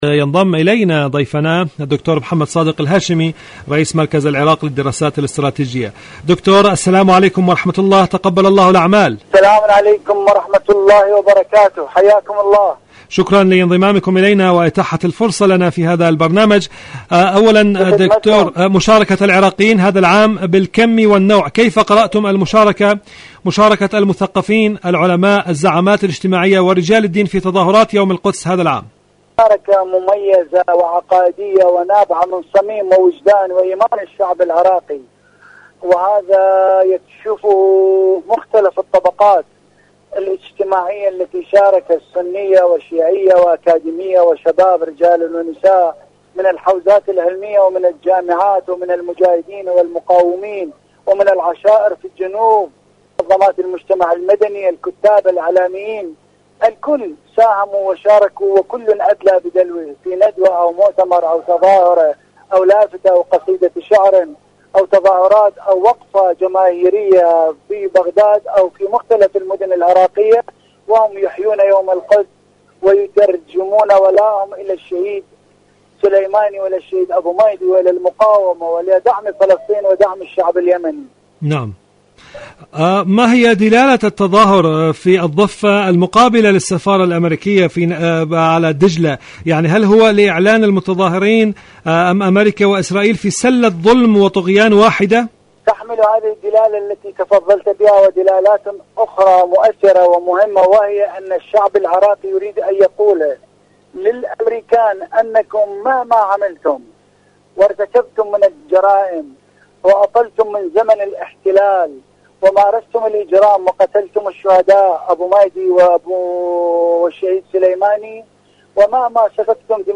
إذاعة طهران-عراق الرافدين: مقابلة إذاعية